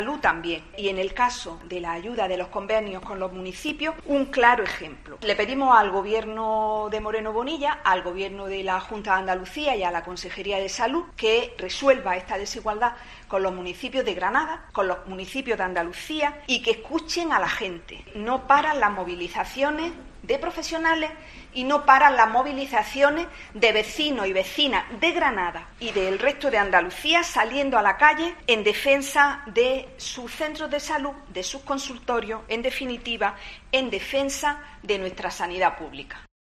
Ángeles Prieto, parlamentaria del PSOE